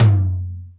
ROCK TOM LOW.wav